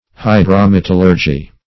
Search Result for " hydrometallurgy" : The Collaborative International Dictionary of English v.0.48: Hydrometallurgy \Hy`dro*met"al*lur`gy\, n. [Hydro-, 1 + metallurgy.] The art or process of assaying or reducing ores by means of liquid reagents.